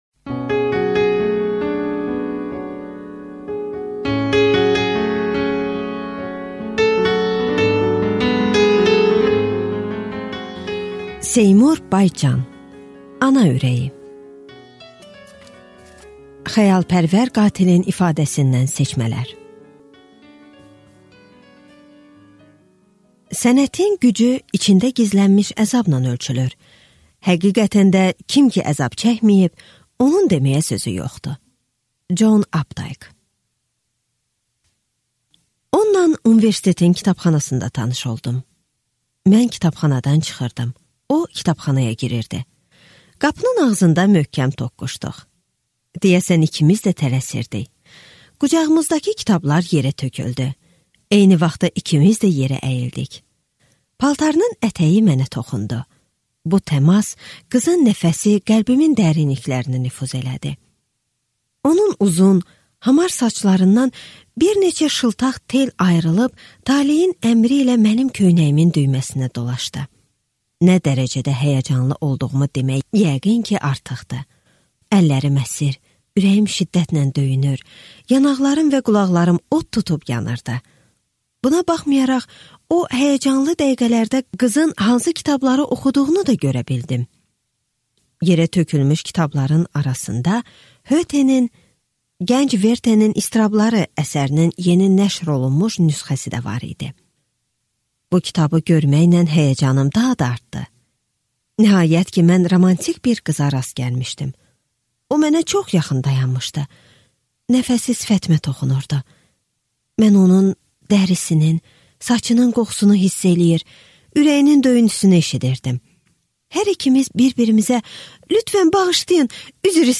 Аудиокнига Ana ürəyi | Библиотека аудиокниг